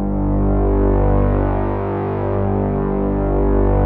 P.5 G#2 7.wav